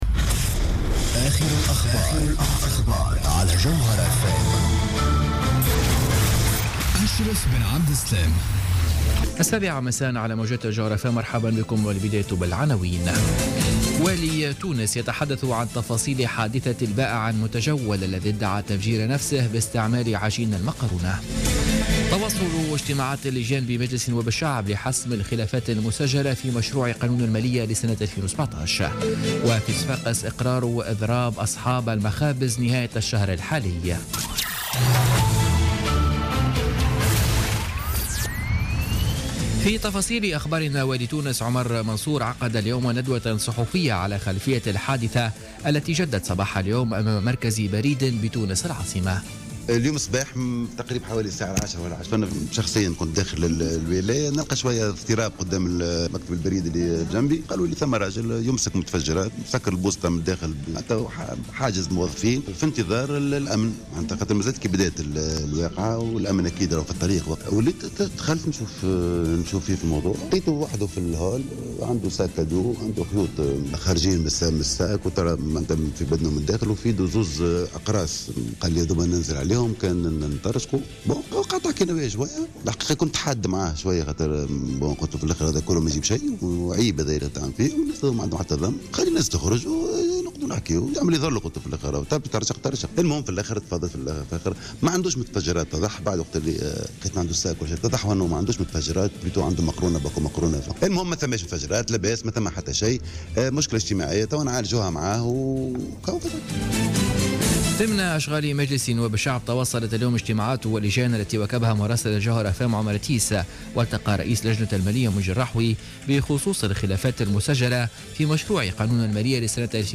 نشرة أخبار السابعة مساء ليوم الأربعاء 16 نوفمبر 2016